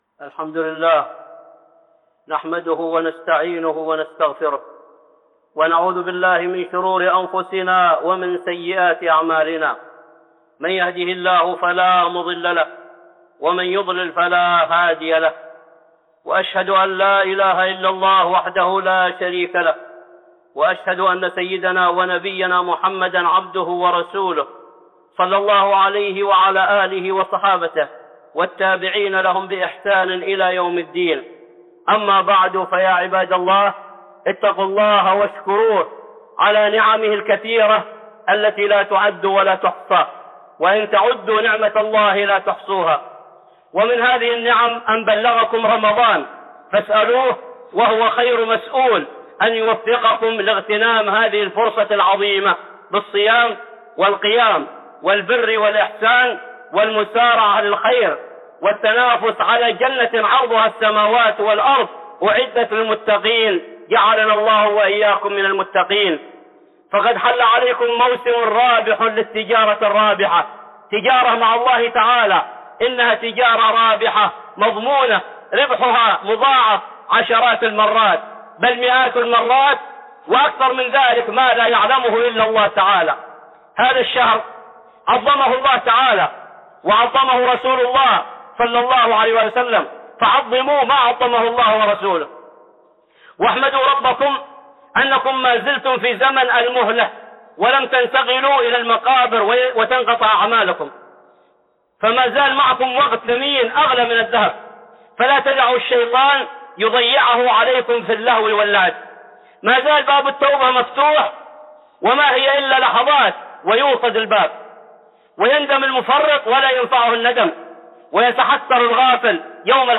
(خطبة جمعة) من أحكام الصيام